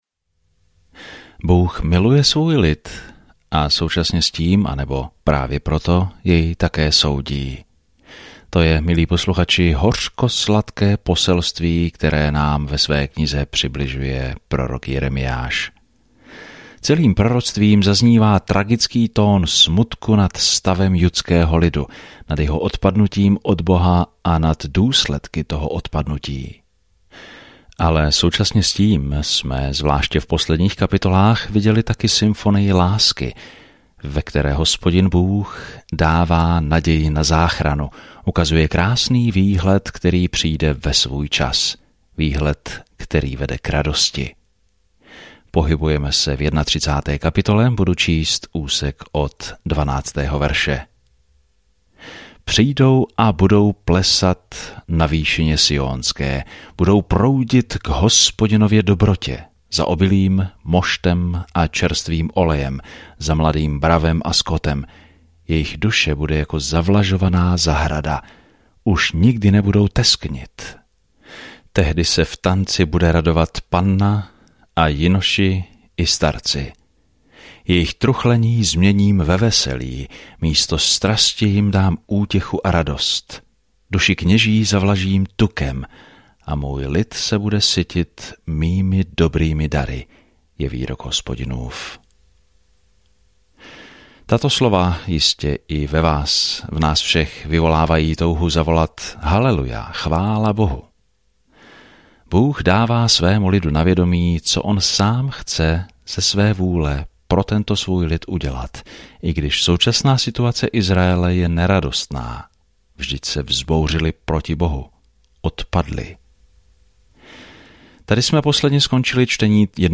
Písmo Jeremiáš 31:15-40 Jeremiáš 32 Den 20 Začít tento plán Den 22 O tomto plánu Bůh si vybral Jeremiáše, muže něžného srdce, aby předal drsné poselství, ale lidé toto poselství nepřijímají dobře. Denně procházejte Jeremiášem, poslouchejte audiostudii a čtěte vybrané verše z Božího slova.